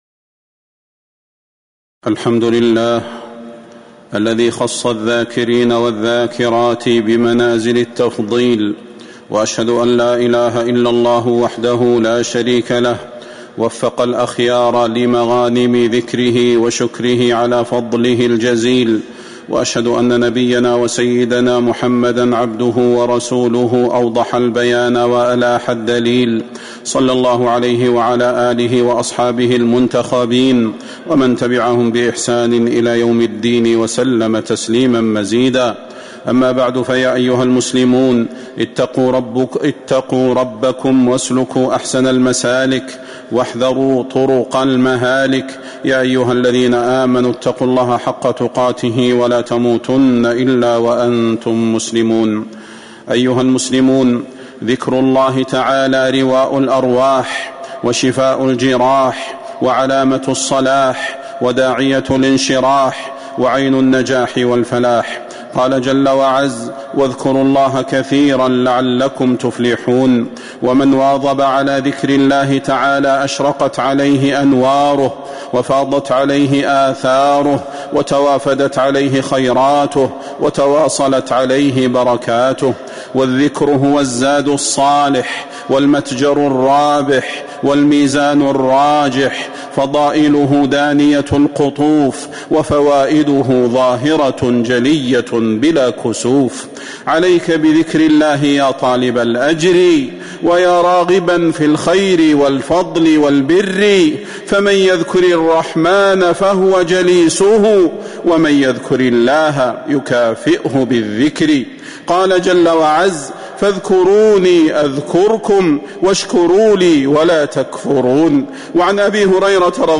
فضيلة الشيخ د. صلاح بن محمد البدير
تاريخ النشر ٩ محرم ١٤٤٧ هـ المكان: المسجد النبوي الشيخ: فضيلة الشيخ د. صلاح بن محمد البدير فضيلة الشيخ د. صلاح بن محمد البدير والذاكرين الله كثيراً والذاكرات